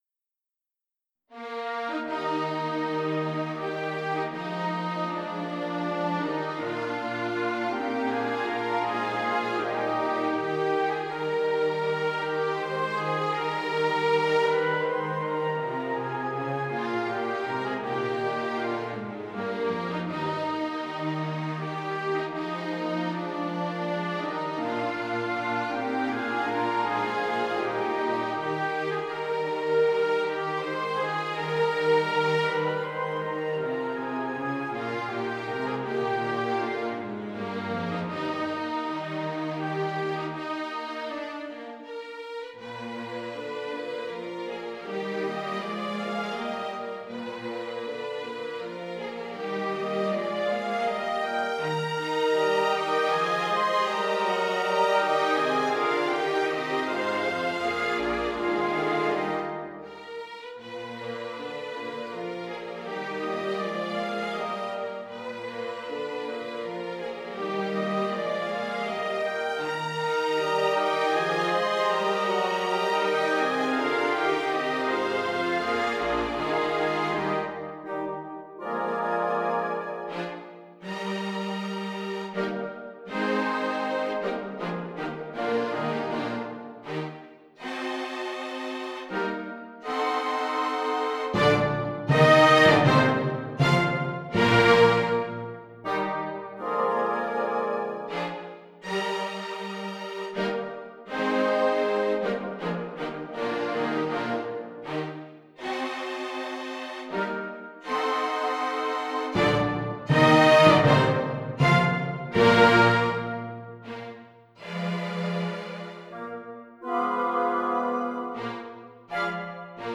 Hi, here a new orchestration - do you guess the original work?